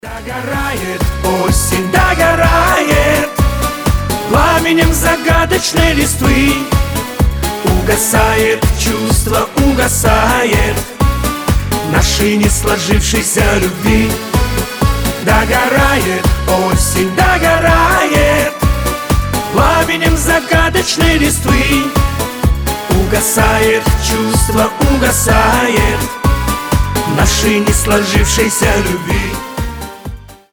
грустные
дуэт